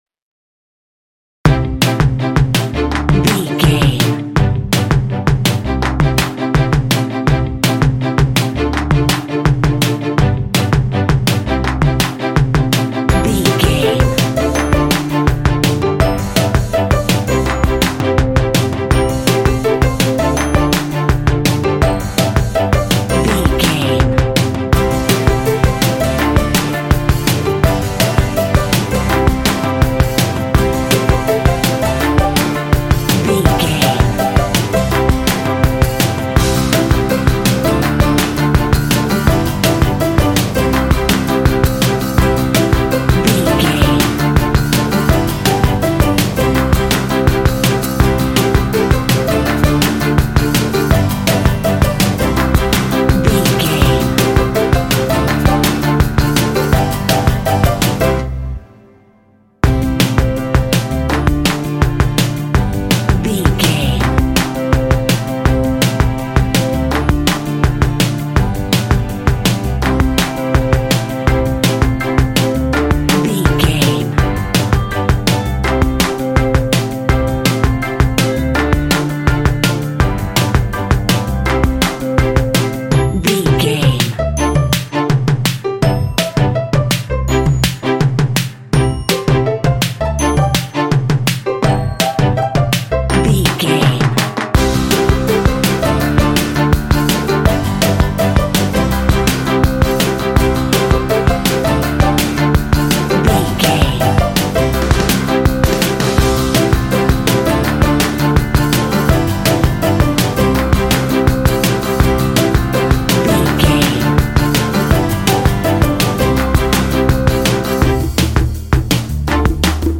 Uplifting
Ionian/Major
bright
happy
inspirational
drum machine
strings
piano
percussion